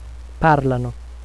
L'accento cade sulla terzultima sillaba con i seguenti suffissi:
pàrlano, vendévano, partìssero, partìssimo
parlano.wav